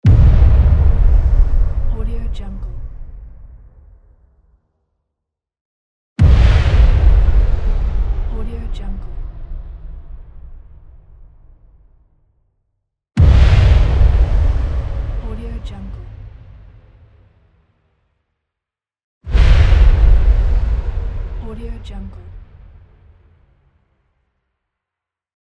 دانلود افکت صوتی اصابت مهیب
یک گزینه عالی برای هر پروژه ای است که به انتقال و حرکت و جنبه های دیگر مانند تاریکی، تاریکی و تعلیق نیاز دارد.